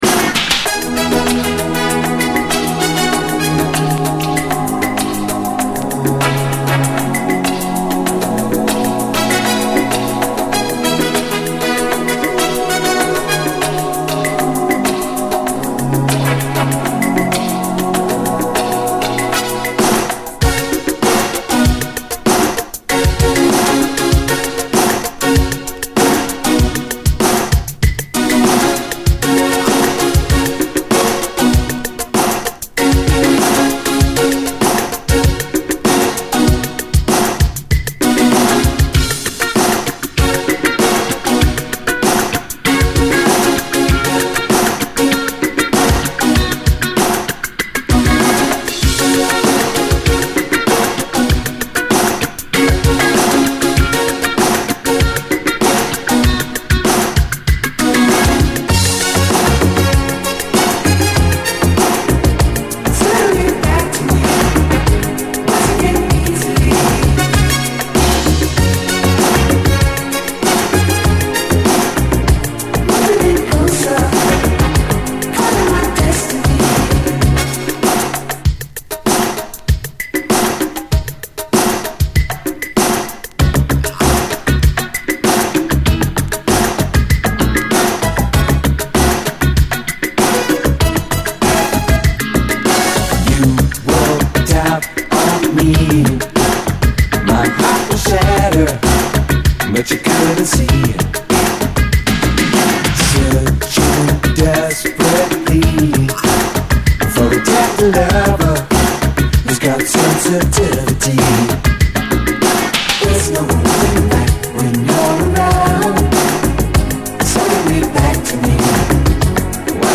DISCO
バレアリック的な儚いシンセ・サウンドの、87年イタリア産メロウ・シンセ・ブギー！
バレアリック的シンセ・サウンドと持ち味の美メロが儚くやるせなく炸裂する、最高メロウ・シンセ・ブギー！